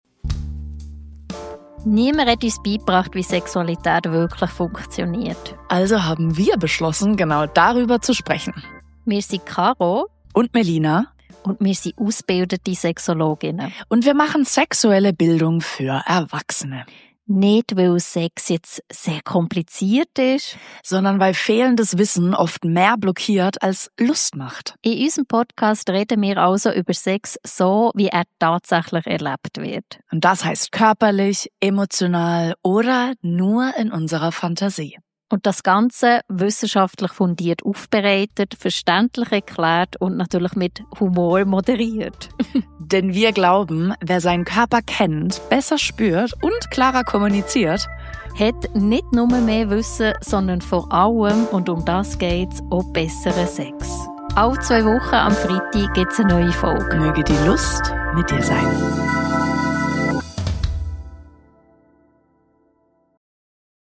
Wir sind zwei ausgebildete Sexologinnen M.A., die davon überzeugt